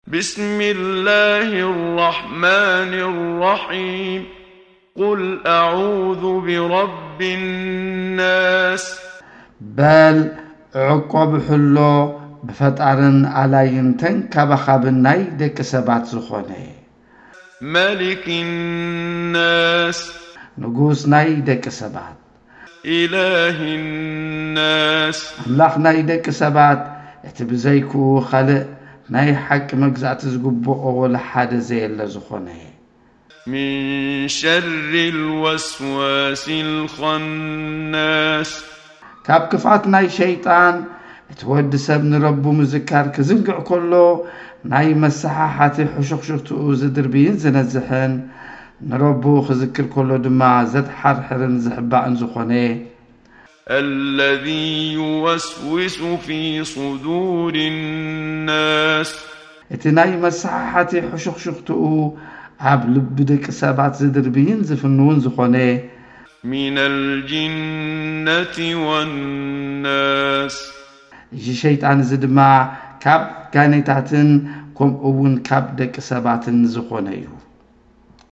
ተግባራዊ ናይ ተጅዊድ ልምምድ ኣብ ሱረት ኣናስ